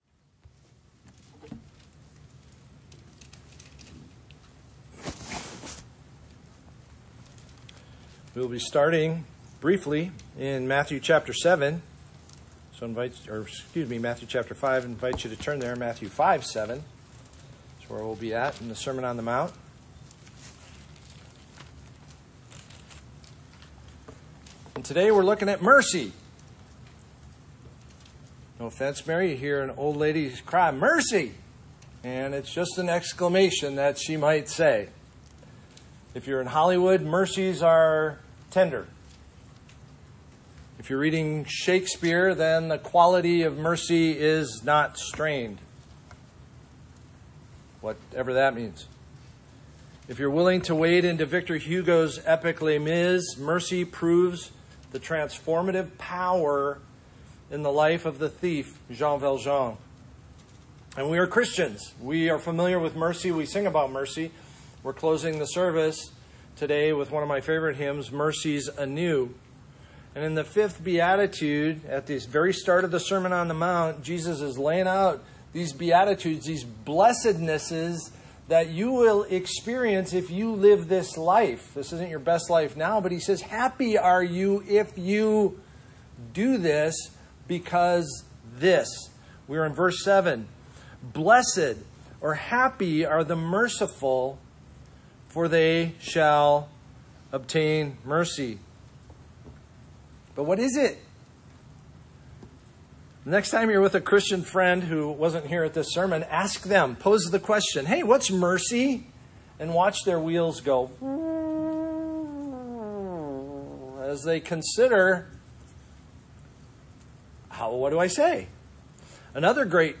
2025 The Magnitude of Mercy Preacher